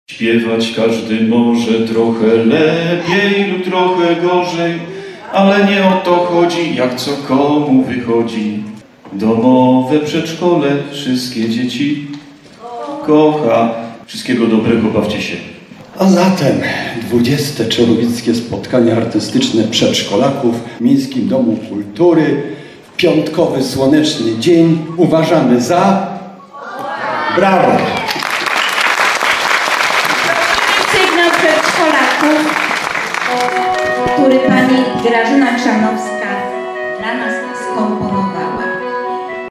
Ten ostatni zrobił to śpiewająco!